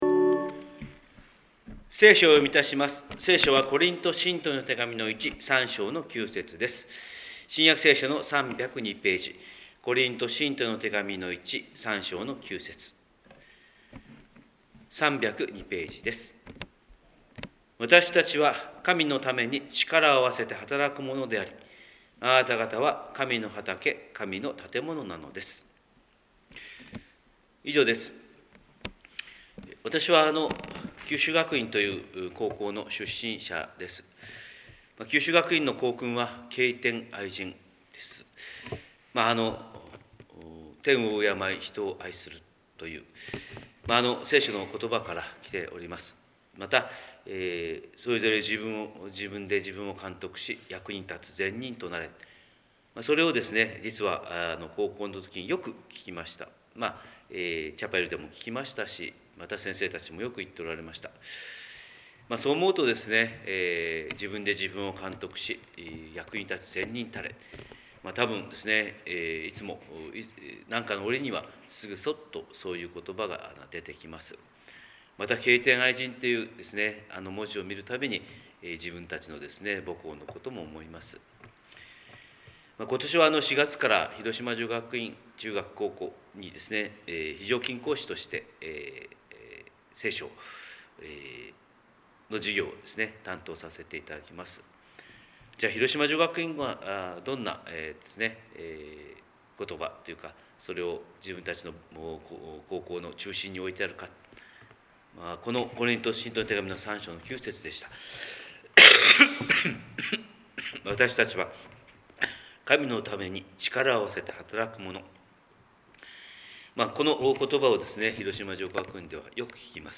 神様の色鉛筆（音声説教）: 広島教会朝礼拝250512
広島教会朝礼拝250512